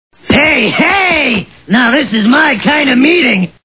The Simpsons [Krusty] Cartoon TV Show Sound Bites